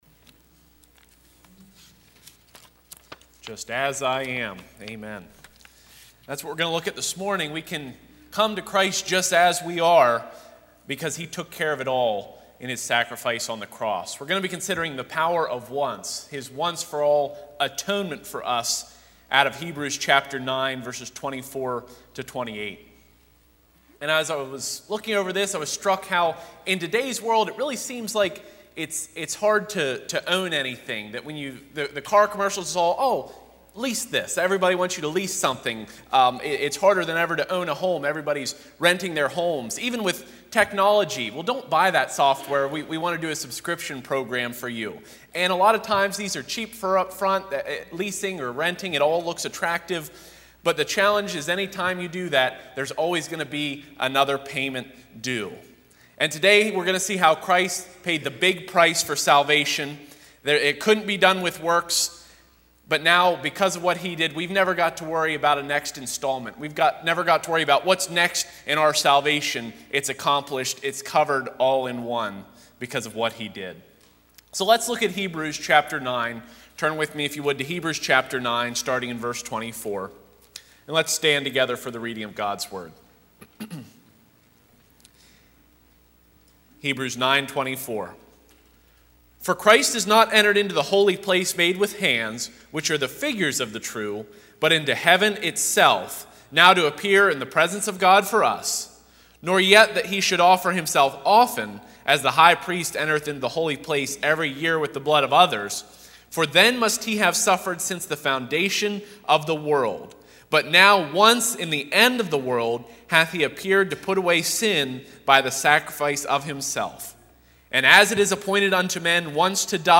Passage: Hebrews 9:24-28 Service Type: Sunday 9:30AM “The Power of Once” I. The Superior Sanctuary II.